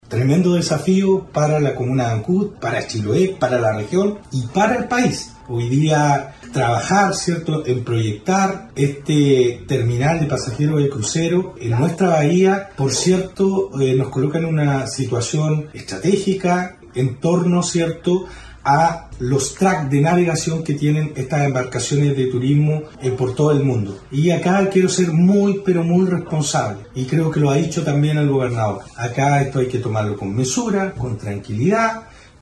Precisamente, el alcalde de esa comuna, Andrés Ojeda, indicó que se trata de un tremendo desafío.